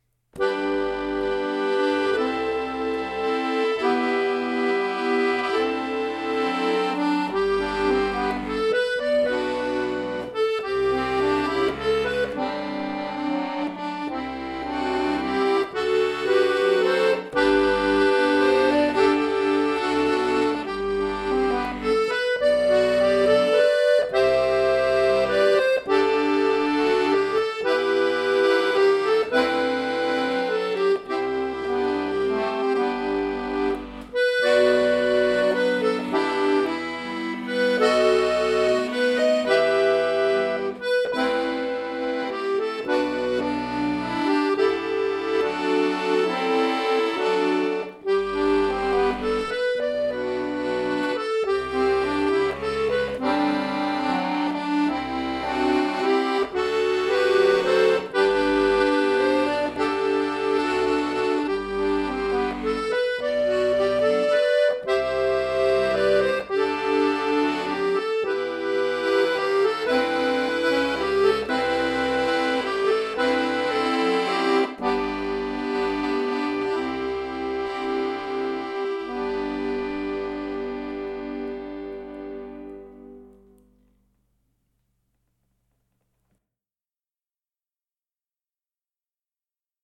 Stimmungsvoller Slow Beat (Originalmusik) für Akkordeon solo